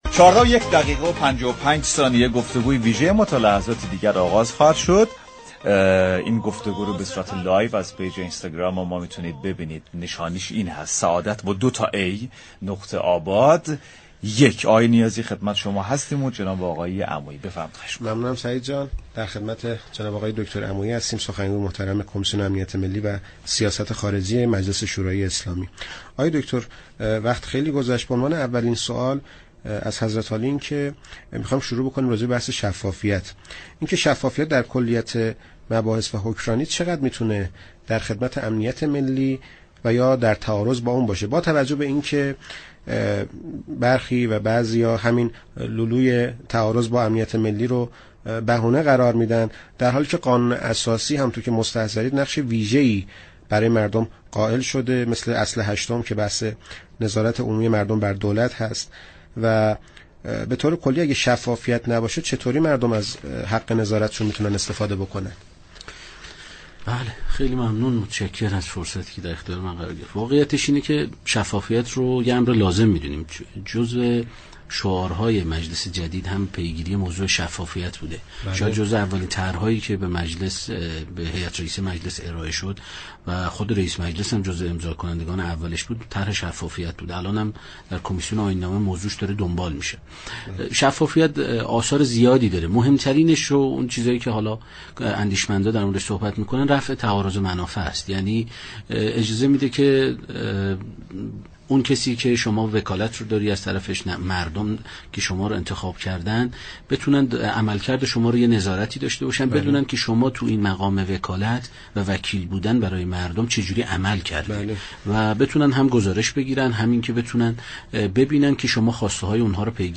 ابوالفضل عمویی، سخنگوی كمیسیون امنیت ملی و سیاست خارجی مجلس شورای اسلامی با حضور در استودیو پخش زنده رادیو تهران درباره امنیت ملی و شفافیت سازی مواردی را مطرح كرد.